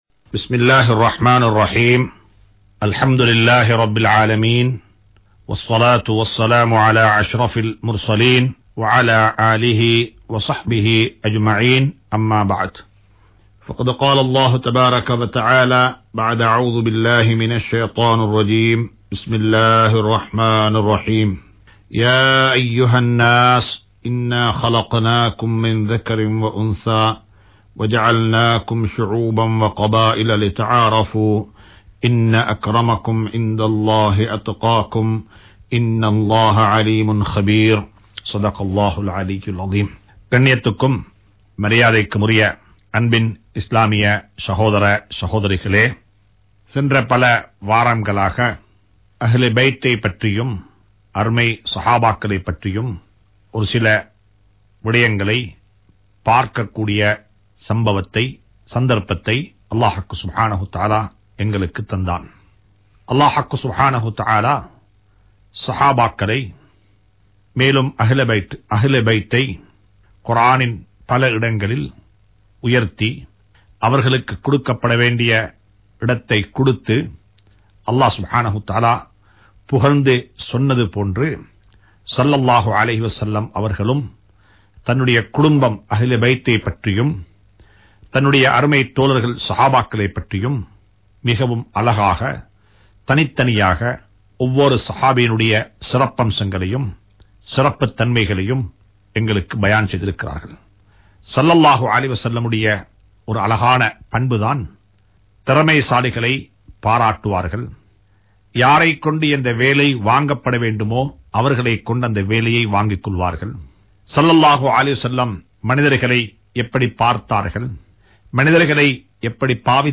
Pirarai Paaraatugal(Thafseer108) | Audio Bayans | All Ceylon Muslim Youth Community | Addalaichenai